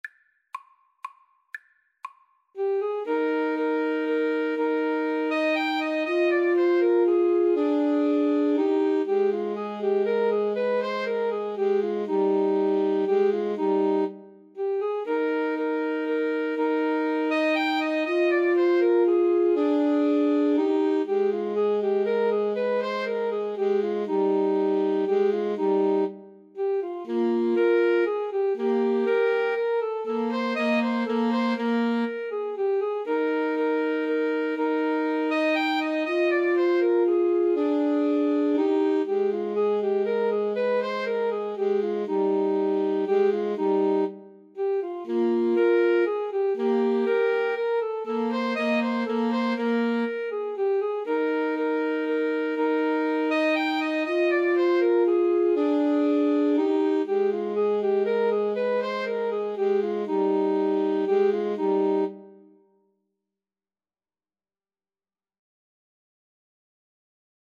3/4 (View more 3/4 Music)
Alto Sax Trio  (View more Easy Alto Sax Trio Music)
Classical (View more Classical Alto Sax Trio Music)